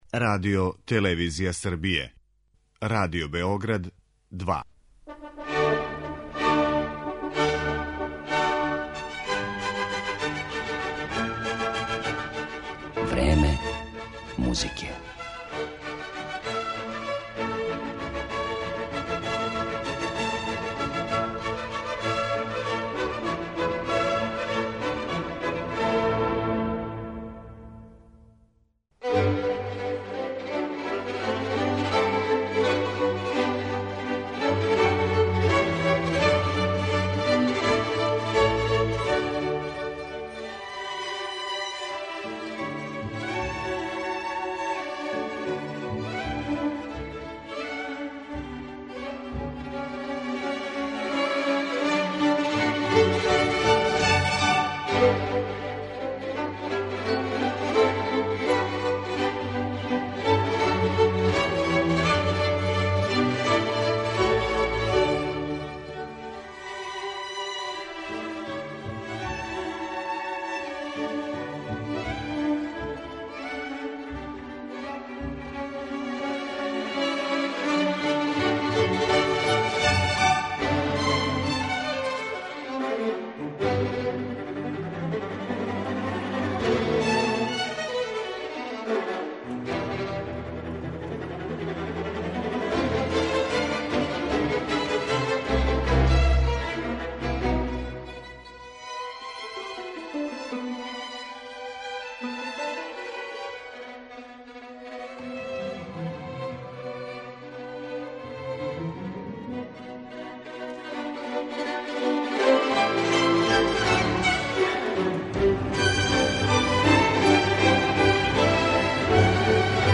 Уз најаву концерта и разговор о активностима оркестра, који постоји од 1992. године, слушаћете и композције Моцарта, Бокеринија, Шостаковича, Грига и Ерића.